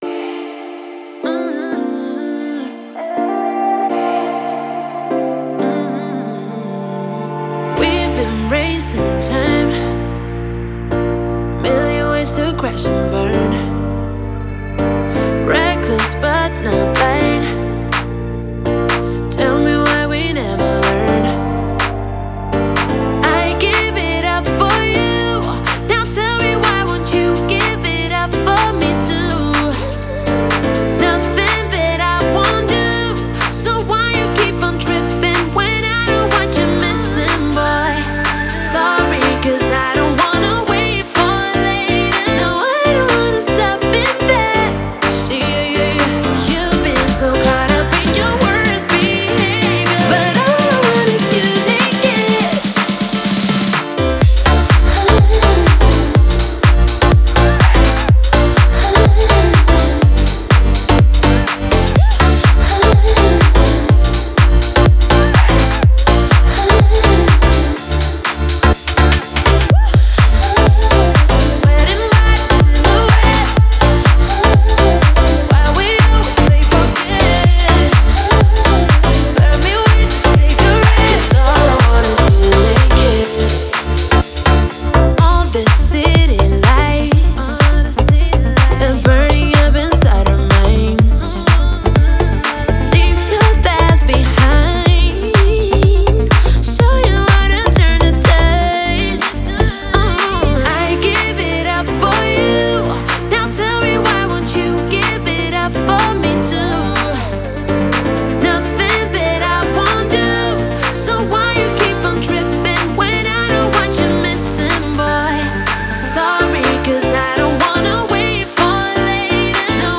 электронная поп-песня